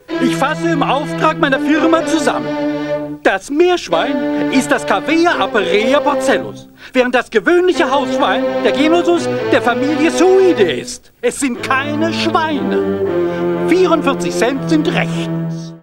Biologieprofessor